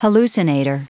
Prononciation du mot hallucinator en anglais (fichier audio)
Prononciation du mot : hallucinator